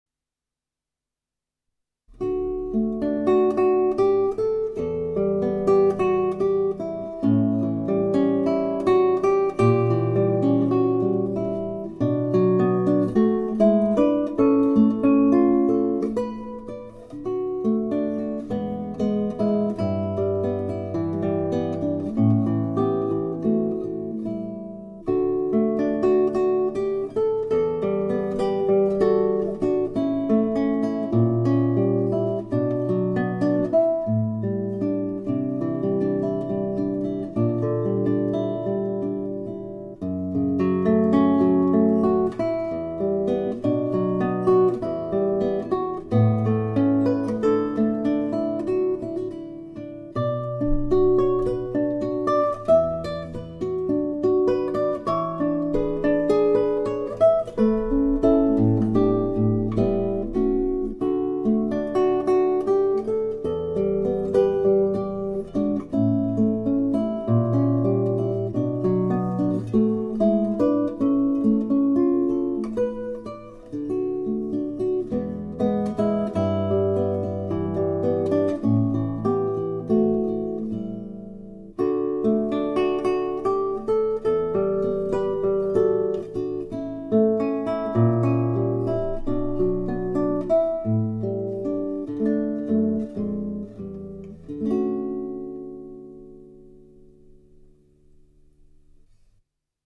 17 Charakterstücke für Konzertgitarre